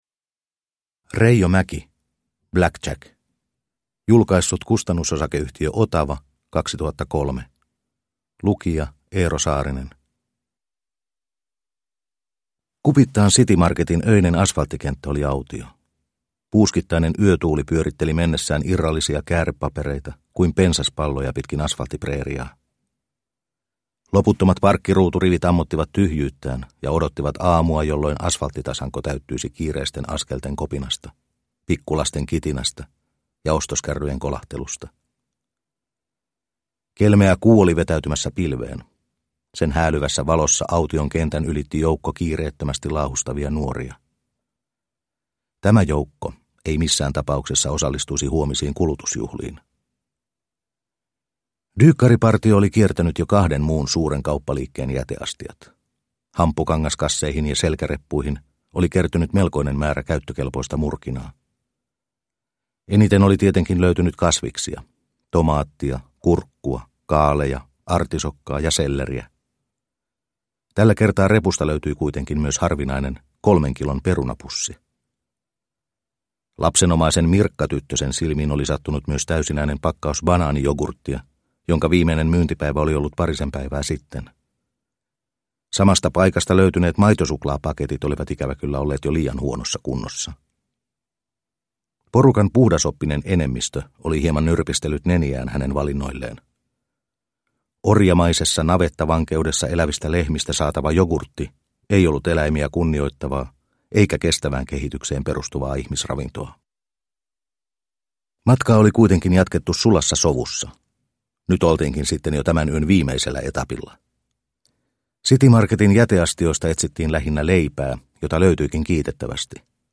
Black Jack – Ljudbok – Laddas ner